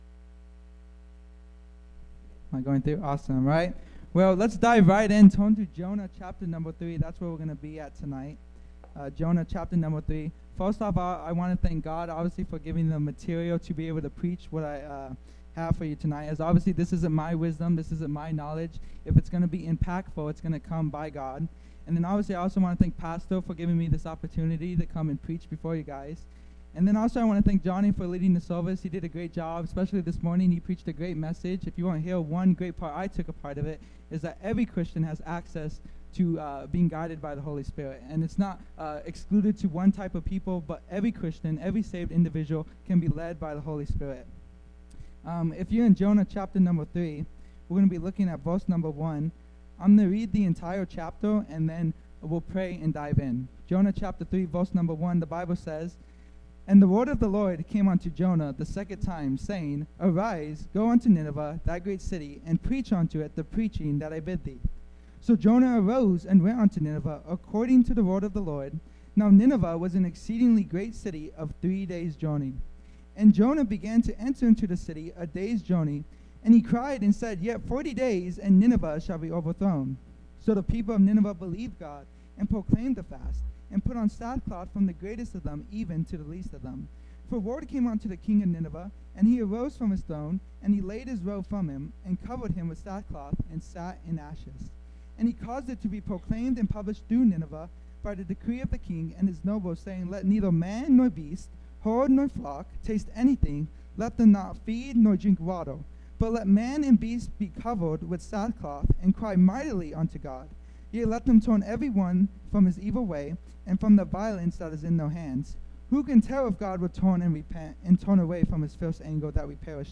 Sermons | Victory Baptist Church